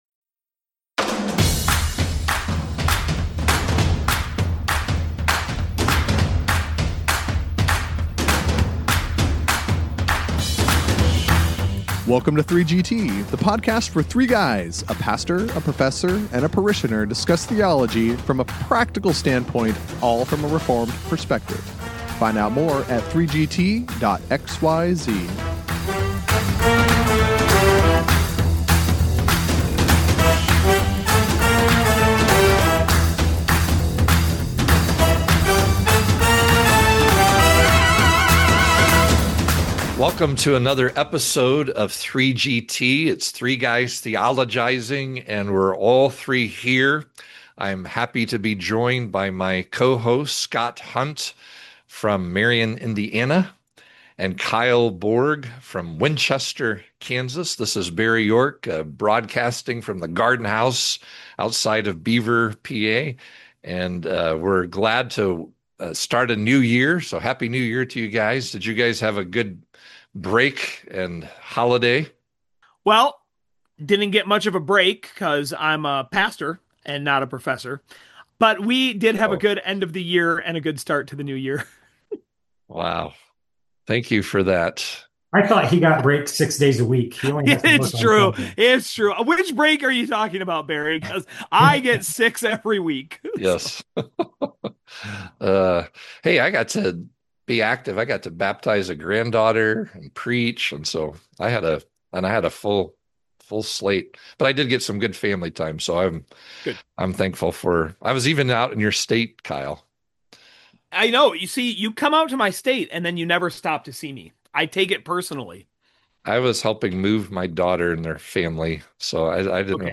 The three guys catch up at the start of 2026.